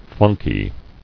[flun·ky]